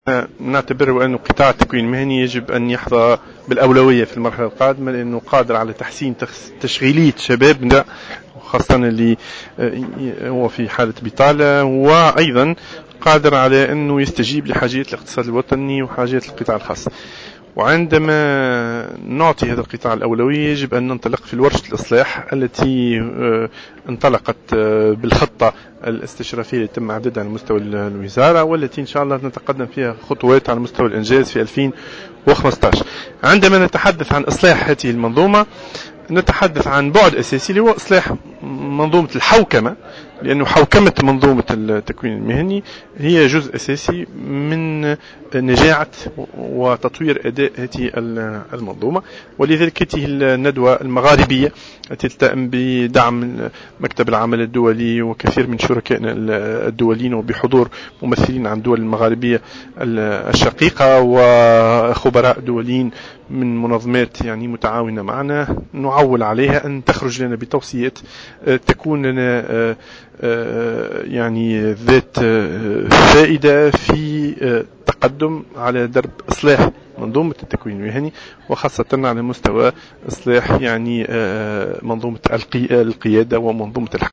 أكد وزير التشغيل زياد العذاري على هامش افتتاح أشغال الندوة المغاربية حول حوكمة منظومات التكوين المهني بالبلدان المغاربية اليوم الخميس أن قطاع التكوين المهني يجب أن يحظى بالأولوية في المرحلة القادمة لأنه قادر على تحسين تشغيلية الشباب العاطل عن العمل والإستجابة الى حاجيات الإقتصاد الوطني والقطاع الخاص.